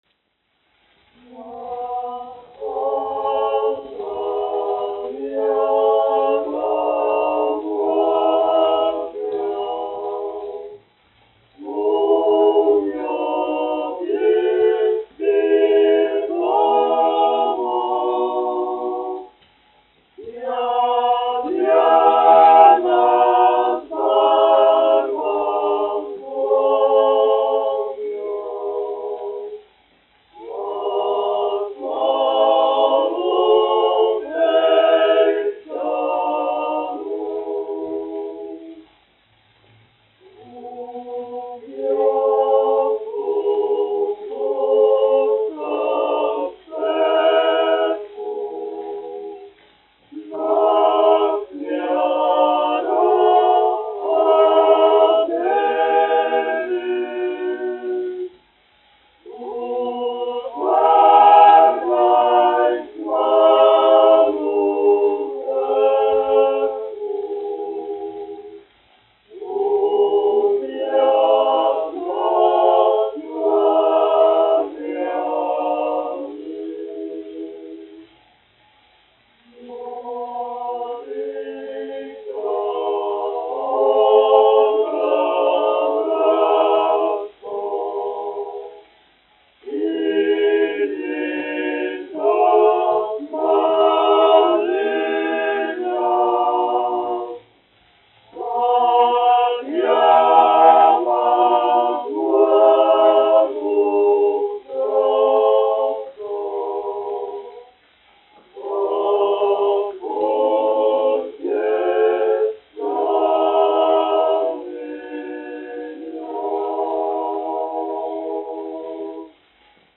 Rīgas Latviešu dziedāšanas biedrības jauktais koris, izpildītājs
1 skpl. : analogs, 78 apgr/min, mono ; 25 cm
Kori (vīru)
Garīgās dziesmas
Skaņuplate